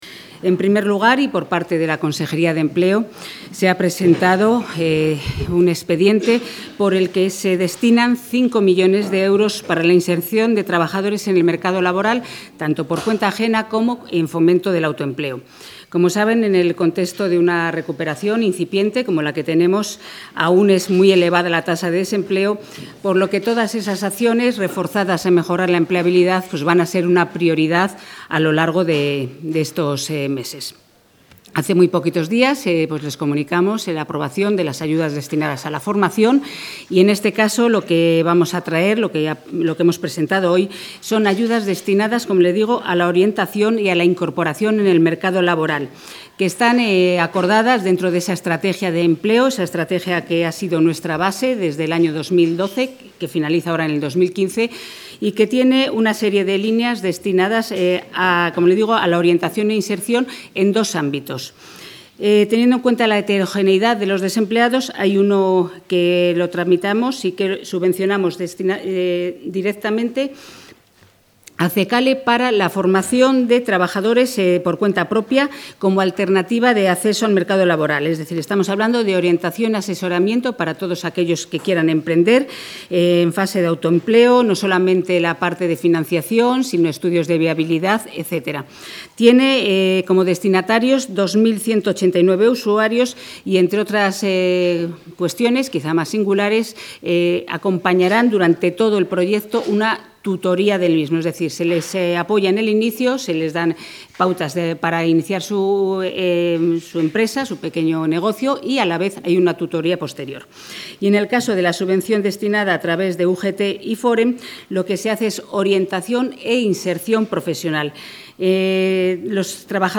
Audio de la rueda de prensa tras el Consejo de Gobierno.